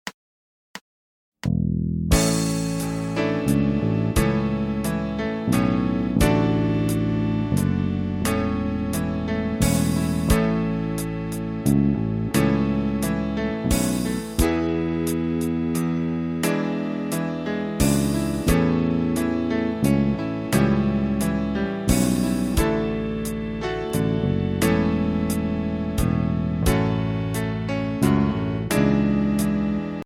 Voicing: Electric Bass